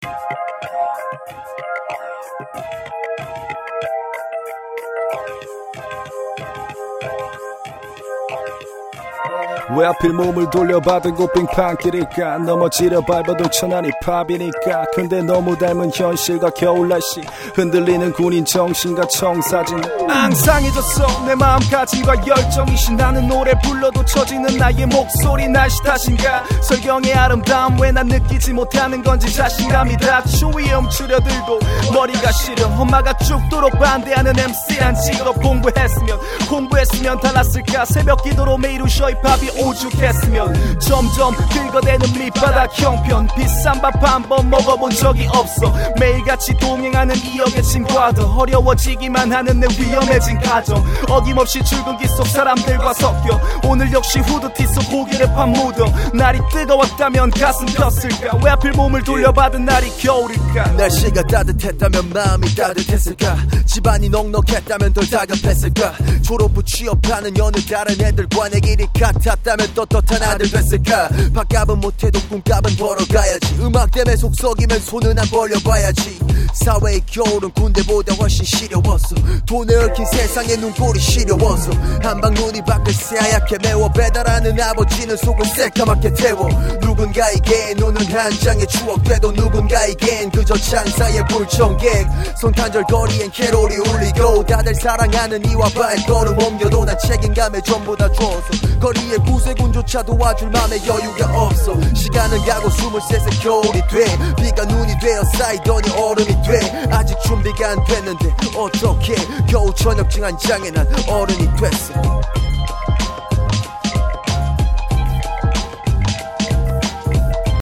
• [REMIX.]